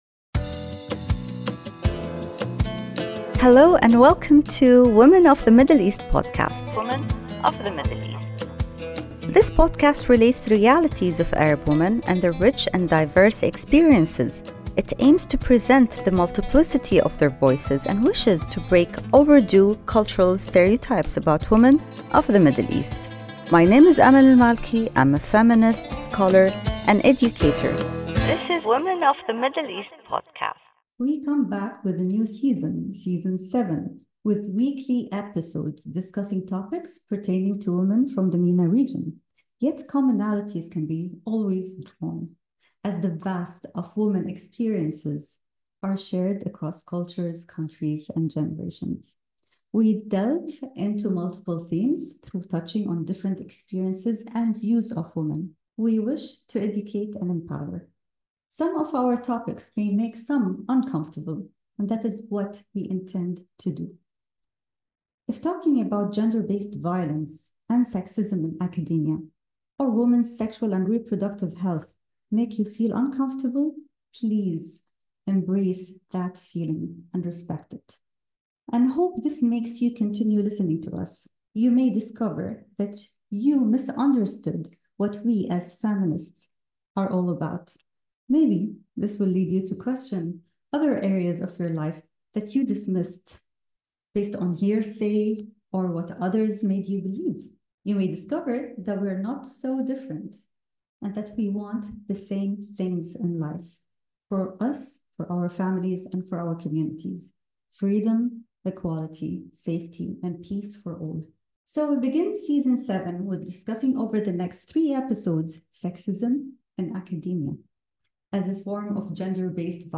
S7E1: Sexism in Academia – A Conversation on Gender and Power